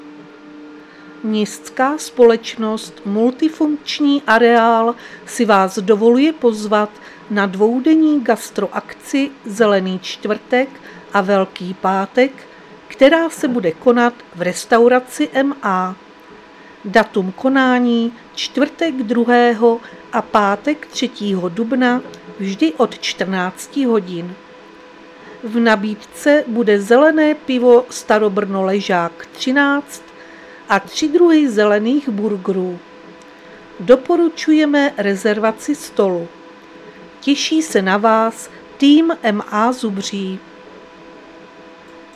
Záznam hlášení místního rozhlasu 1.4.2026
Zařazení: Rozhlas